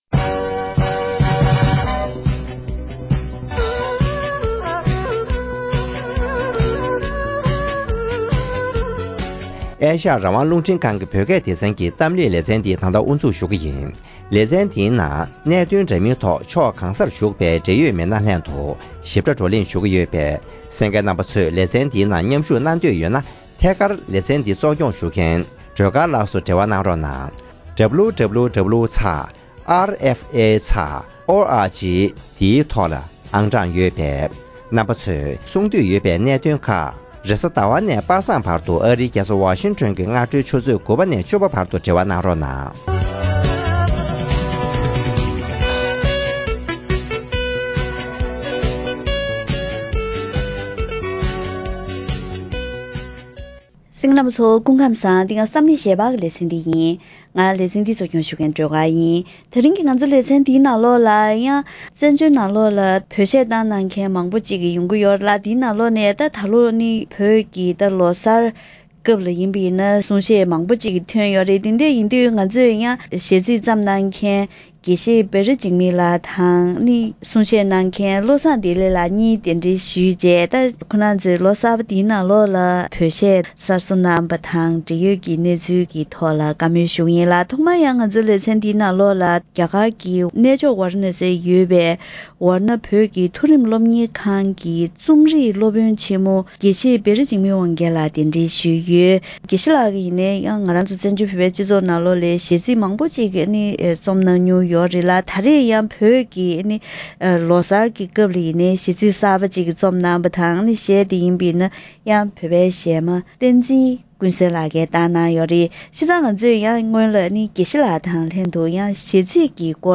ཐེངས་འདིའི་གཏམ་གླེང་ལེ་ཚན